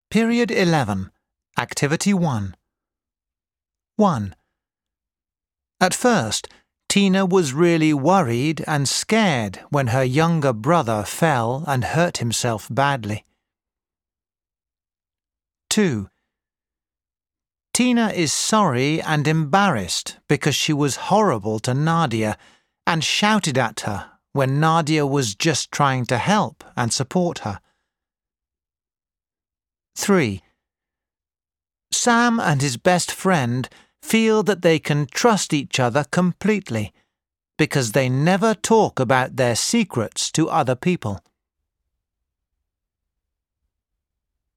دروس الاستماع